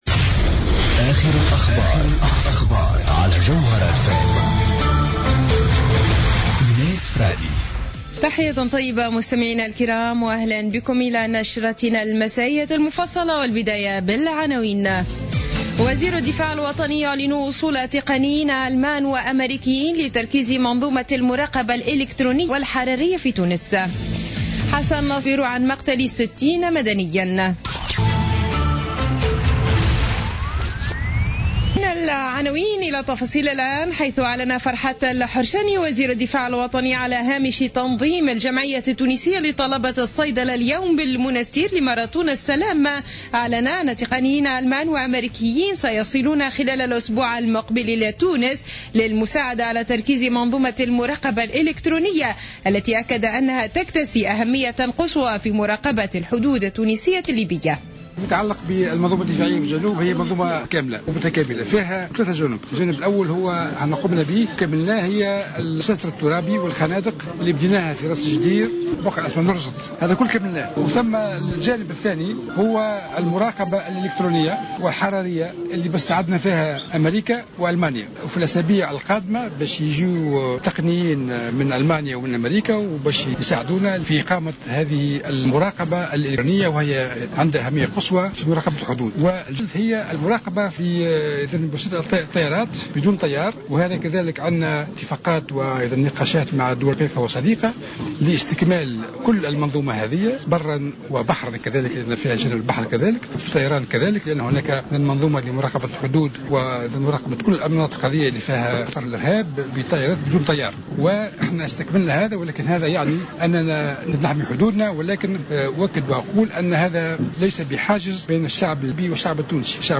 نشرة أخبار السابعة مساء ليوم الأحد 6 مارس 2016